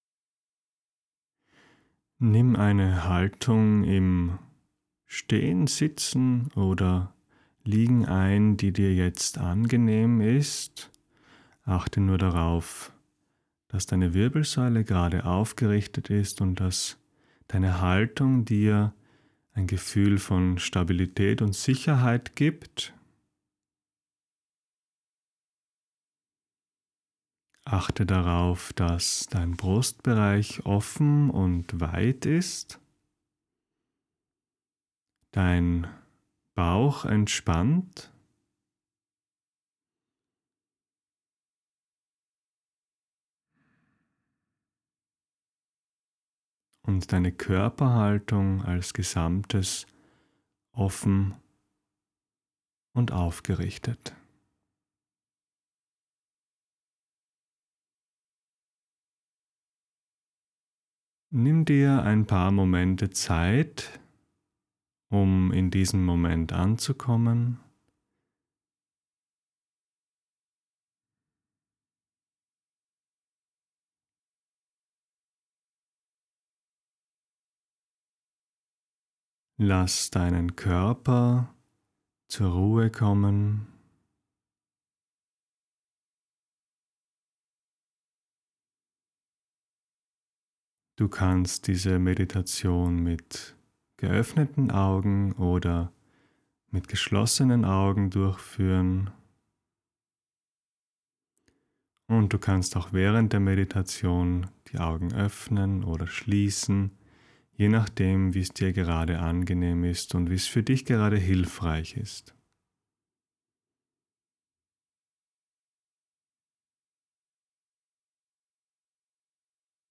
Nachweihnachtsmeditation – Die Wurzel
NachweihnachtsmeditationSpontan.mp3